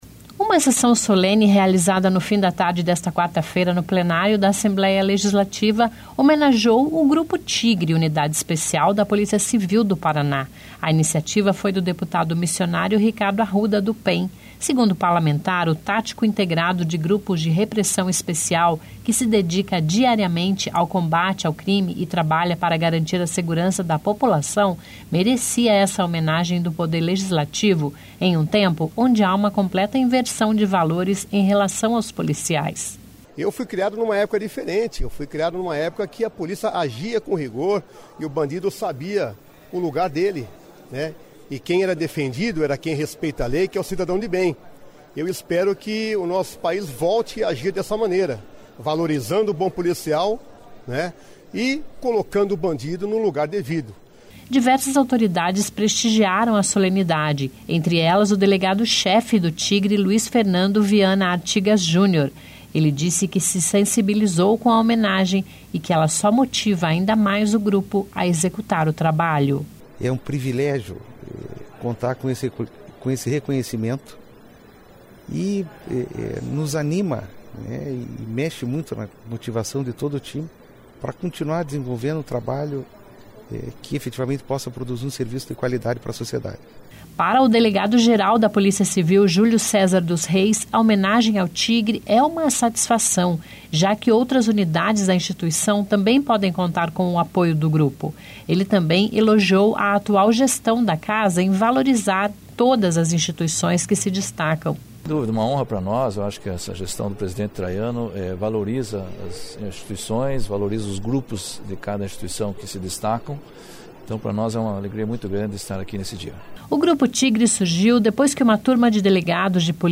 (Descrição do áudio)) Uma sessão solene realizada no fim da tarde desta quarta-feira (18), no Plenário da Assembleia Legislativa homenageou o Grupo Tigre, unidade especial da Polícia Civil do Paraná. A iniciativa foi do deputado Missionário Ricardo Arruda (PEN).